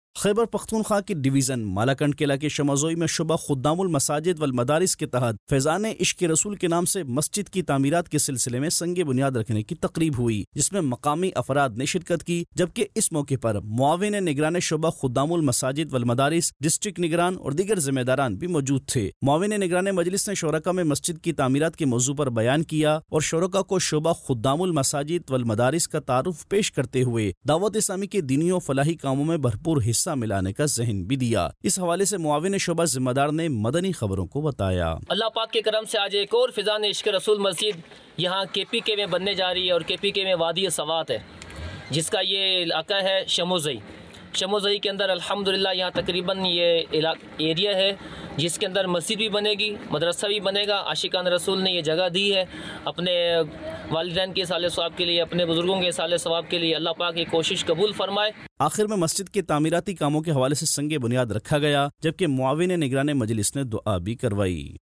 News Clips Urdu - 01 December 2022 - Shoba Khadam ul Masjid ul Madaris Kay Tehat Faizan e Ishq e Rasool Masjid Ka Sang e Bunyad Dec 16, 2022 MP3 MP4 MP3 Share نیوز کلپس اردو - 01 دسمبر 2022 - شعبہ خدام المساجدو المدارس کے تحت فیضانِ عشق رسول مسجد کا سنگِ بنیاد